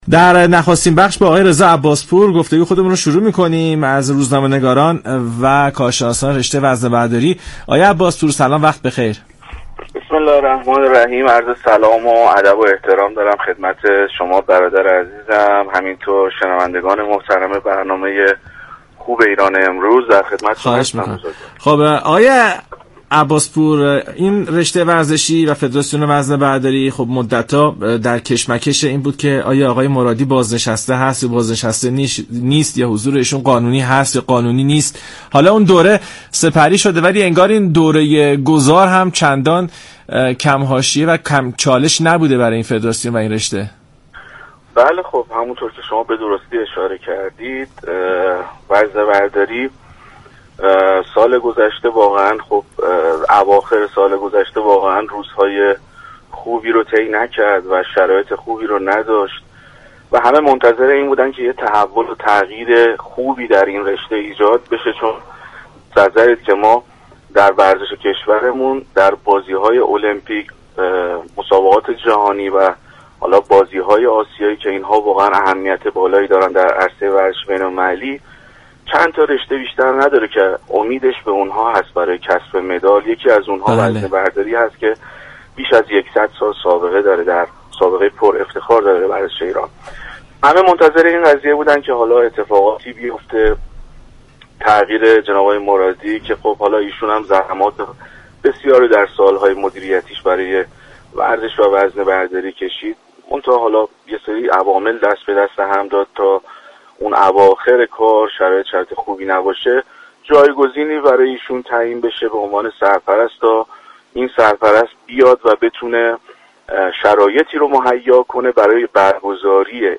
در ادامه این گفت و گوی رادیویی